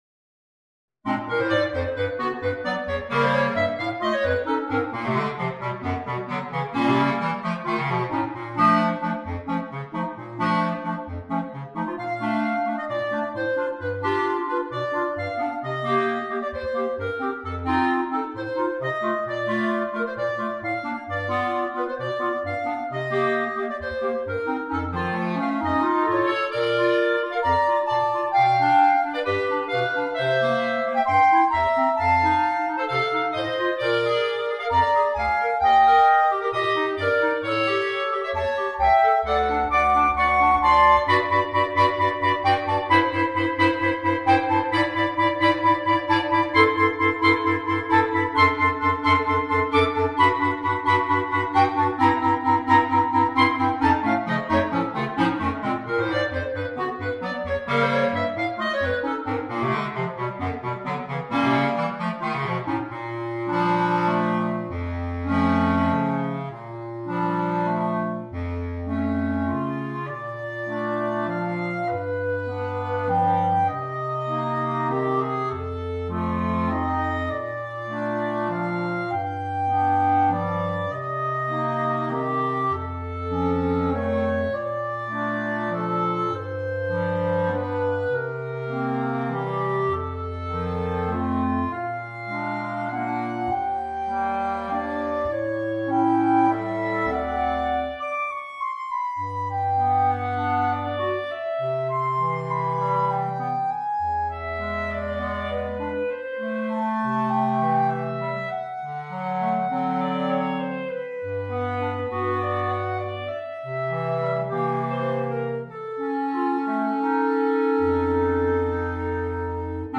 Per quartetto di clarinetti
Un brano con tratti jazzistici per quartetto di clarinetti.